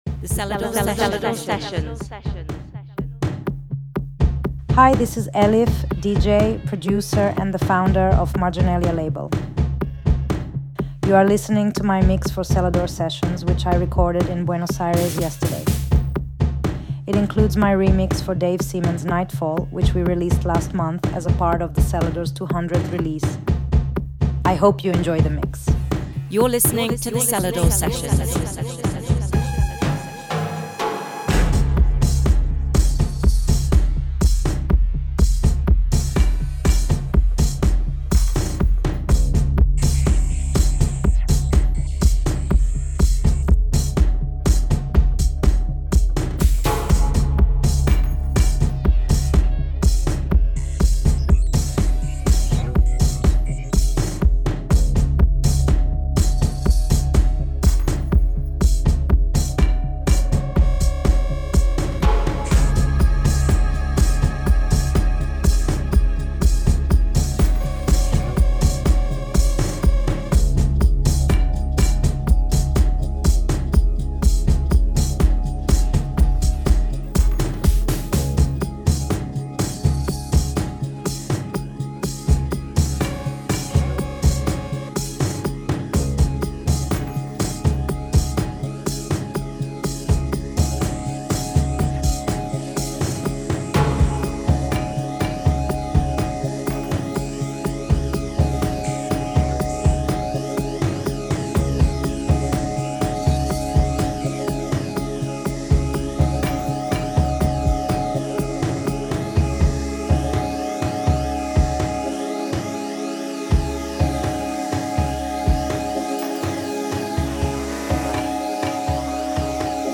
a brand new and exclusive mix
Turkish DJ, Producer and label owner